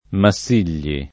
[ ma SS& l’l’i ]